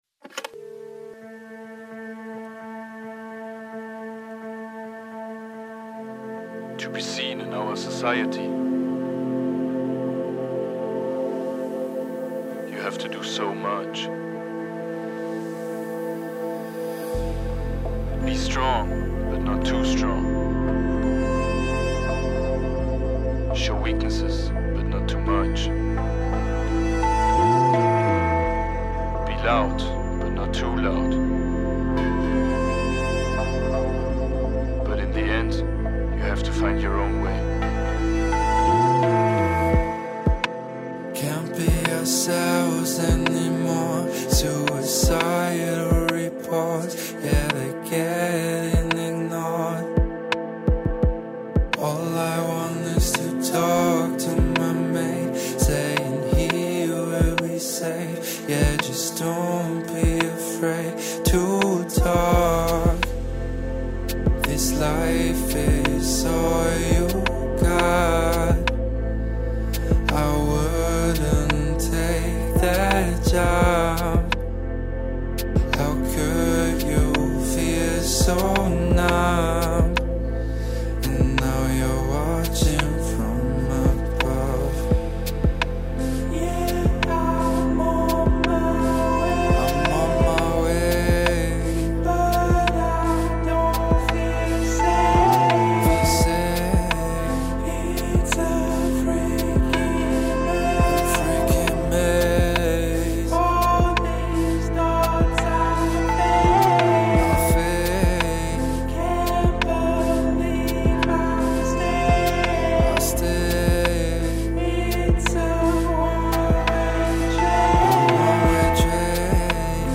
Pop.